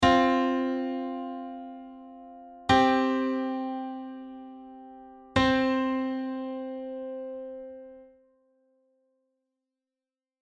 Perfect fourth, perfect fifth and perfect octave harmonic intervals in C-Major:
open_chords.mp3